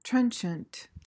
PRONUNCIATION:
(TREN-chuhnt)